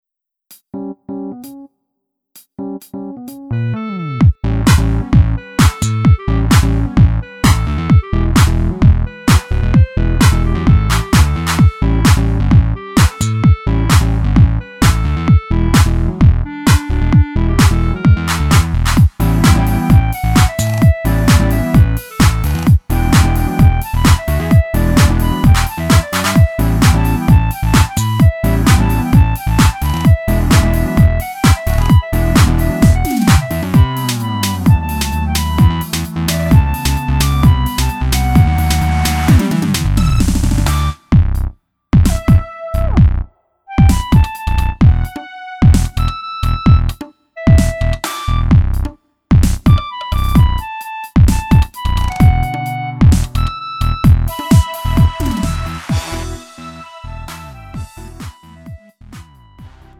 음정 -1키 3:13
장르 구분 Lite MR